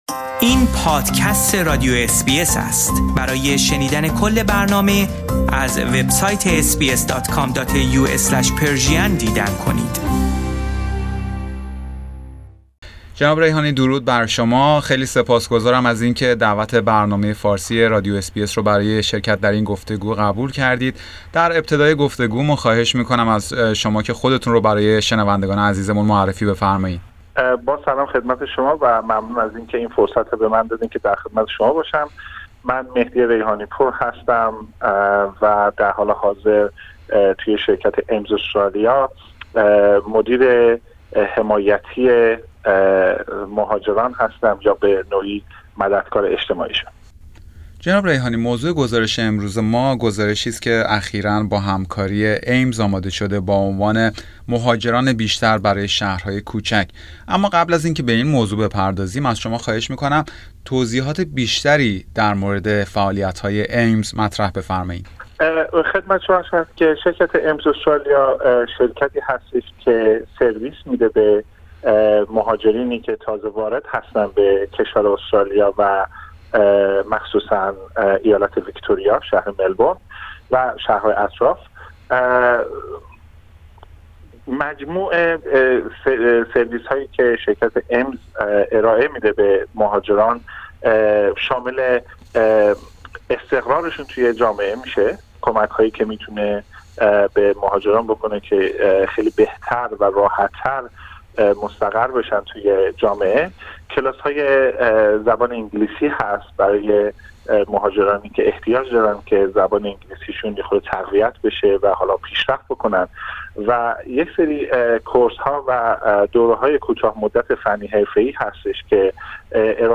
گفتگو با اس بی اس فارسی